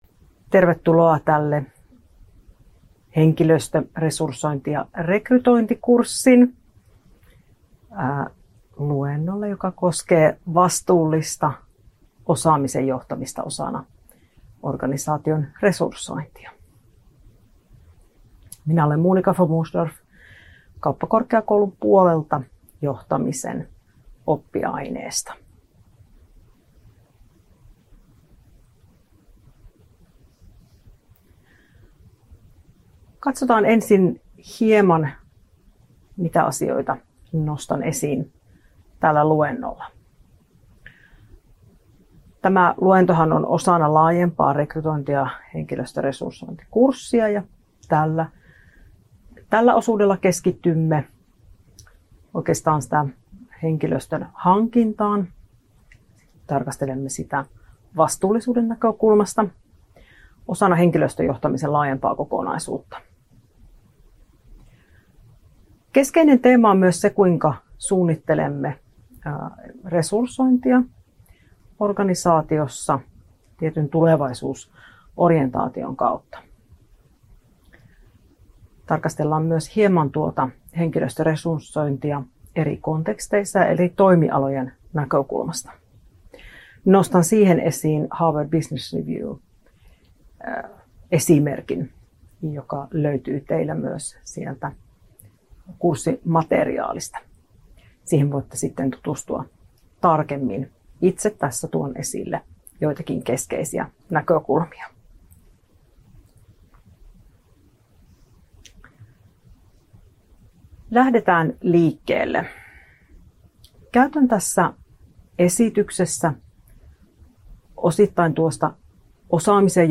YJOA2220 Henkilöstöresursointi ja rekrytointi, luentotallenne aiheesta vastuullinen osaamisen johtaminen osana organisaation resursointia.